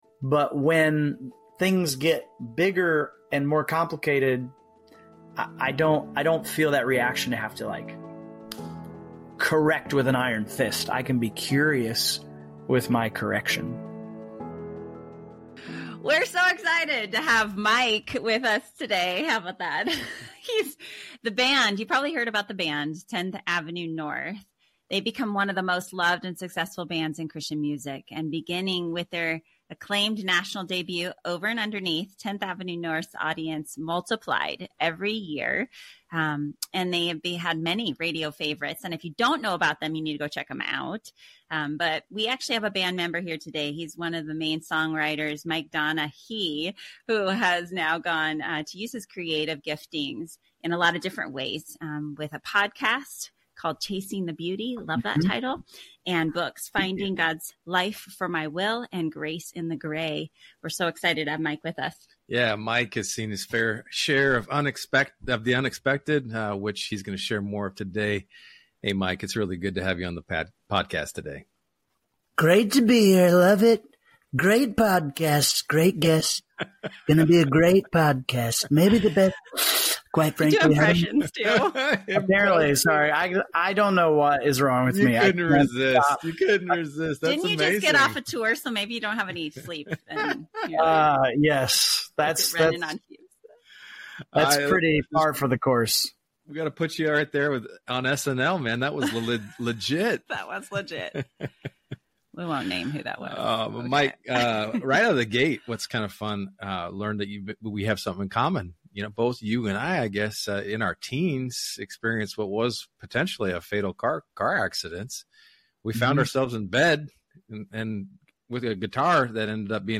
This week, we’re joined by Mike Donehey, former lead singer of Tenth Avenue North, as we dive into the heart of true leadership. Together, we explore how servant leadership, humility, and grace shape our ministry and relationships. Mike shares his journey of realizing that platform and fame aren't the true markers of success—it's about stewarding our gifts with authenticity and love.